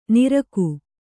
♪ niraku